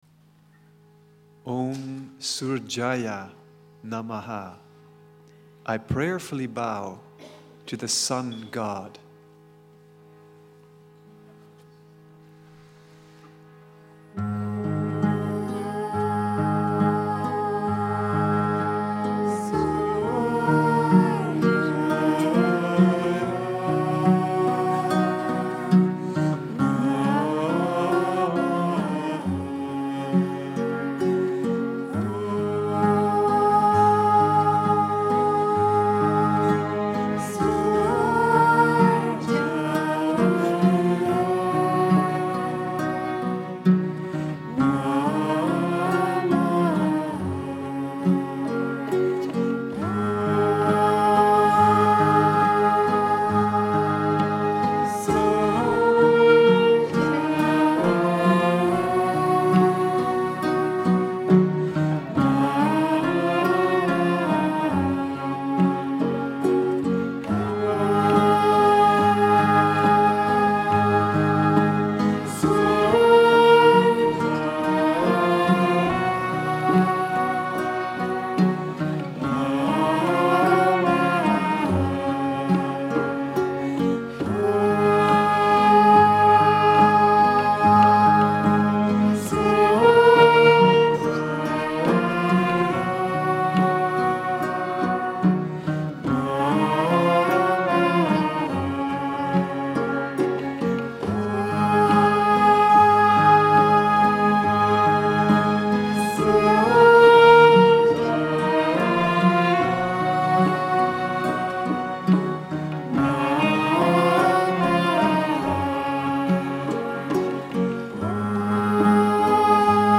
Mantric singing from Greece Concert | Radio Sri Chinmoy
At a recent concert, musicians from the Sri Chinmoy Centre performed 13 short mantras, composed by Sri Chinmoy. The simple, but soulful mantras were sung towards the end of a two-hour Concert in Athens, attended by around 400 people.
The mantras were performed by an international group of musicians
the haunting sound
flute playing
the powerful cello